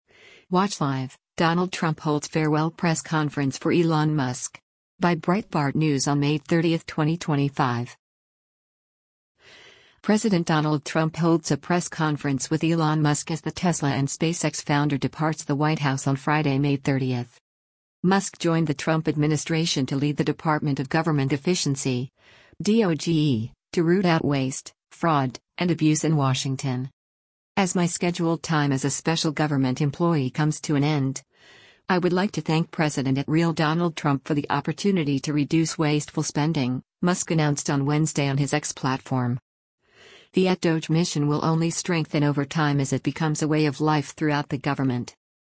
President Donald Trump holds a press conference with Elon Musk as the Tesla and SpaceX founder departs the White House on Friday, May 30.